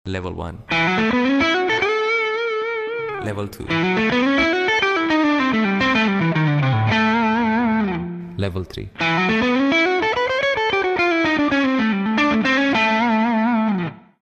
playing guitar like a professional